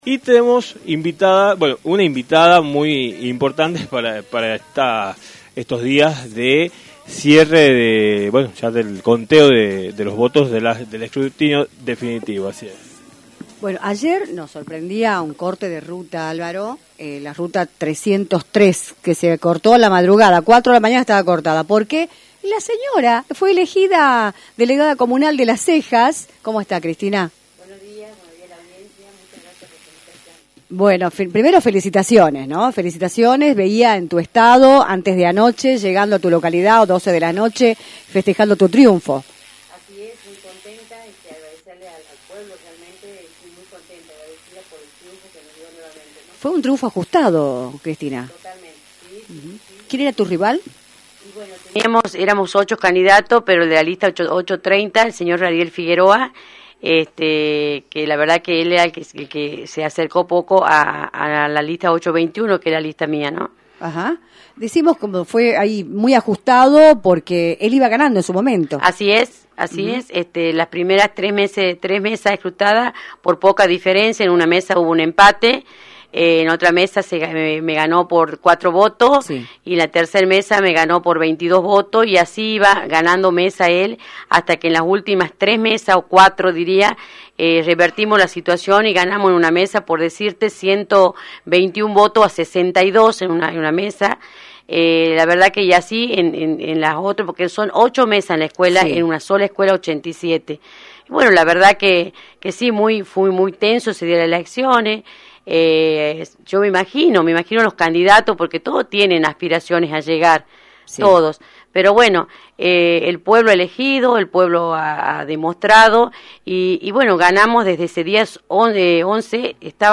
Cristina Contreras, Comisionada Comunal electa de Las Cejas, Tucumán, visitó los estudios de “Libertad de Expresión”, por la 106.9, luego de que se produjeran cortes en la Ruta 303 una vez que el escrutinio definitivo diera a Contreras como ganadora de las elecciones realizadas el 11 de junio por 18 votos de diferencia.